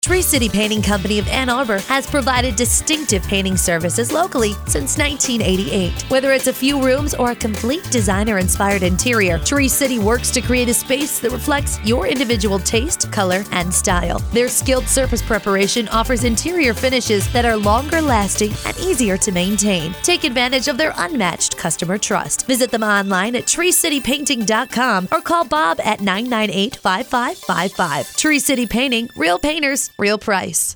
Click above to listen to our new radio spot.
tree-city-painting-radiospot.mp3